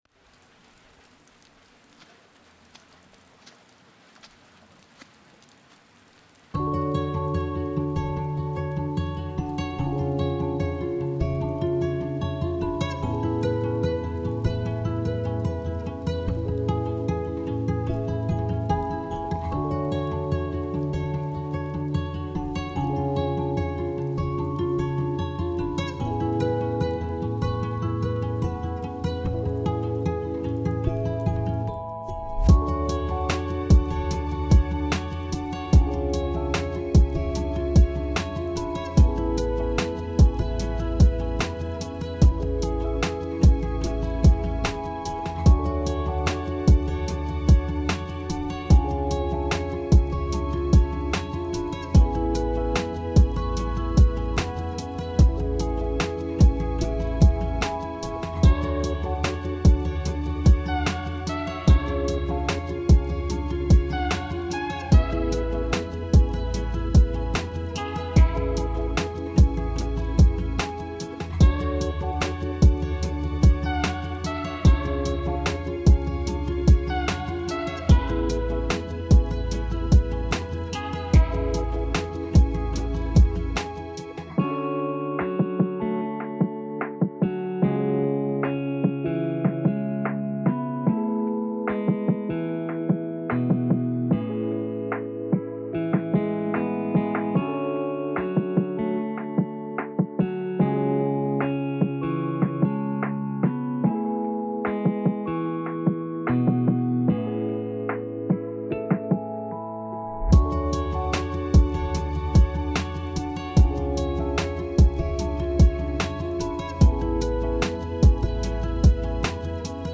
Lo-Fi Feel free to use my music